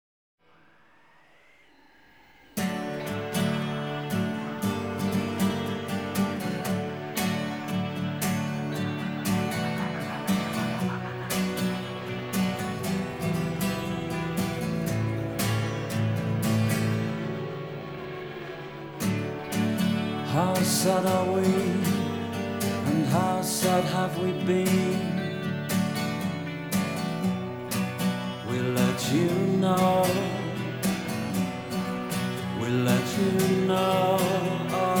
Alternative Rock Adult Alternative College Rock
Жанр: Рок / Альтернатива